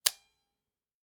camera_click.ogg